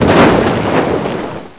bang21.mp3